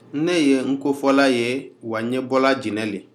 Dialogue: [nko]ߒߞߏ ߞߊ߲ ߝߐ[/nko]
This is a dialogue of people speaking Nko as their primary language.